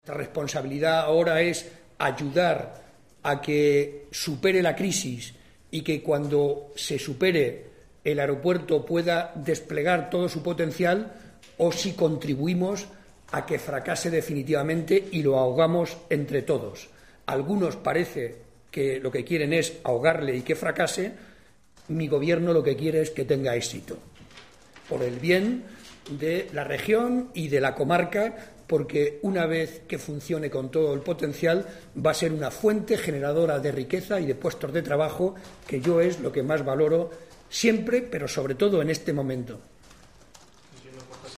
JCCM Jueves, 11 Marzo 2010 - 1:00am El presidente de Castilla-La M an cha, José María Barreda, atendió hoy a los medios de comunicación en Toledo y en respuesta a sus preguntas aseguró sobre el Aeropuerto Central de Ciudad Real que la repsonsabilidad ahora es ayudar a que superes la crisis y que cuando así sea este pueda deseplegar todo su potencial.